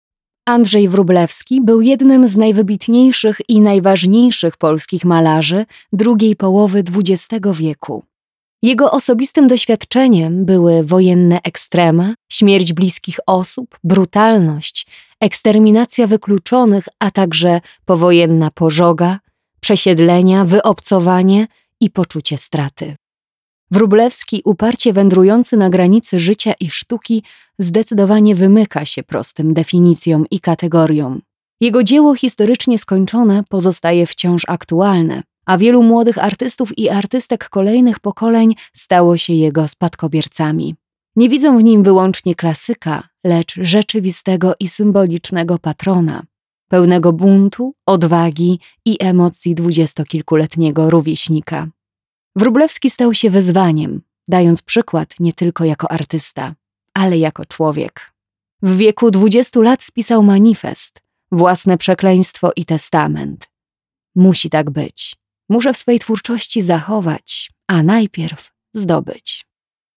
Commercial, Natural, Friendly, Warm, Soft
Audio guide